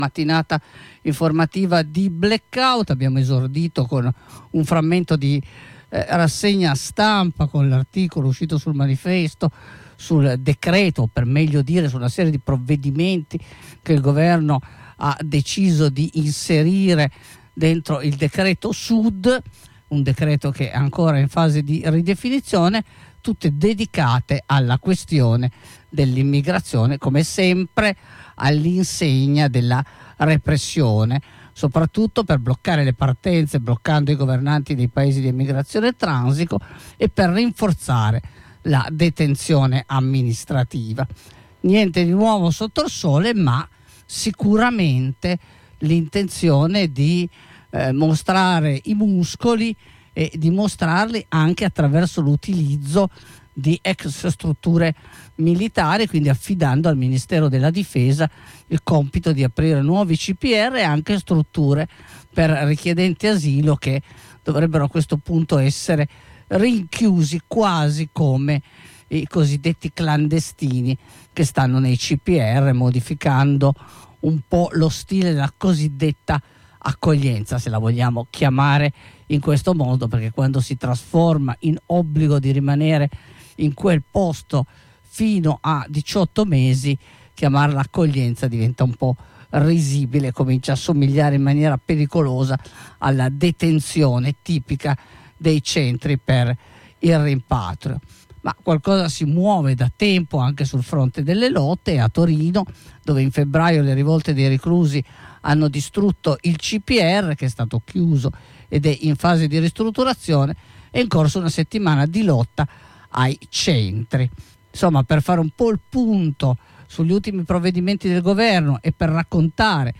né qui né altrove di Torino Ascolta la diretta: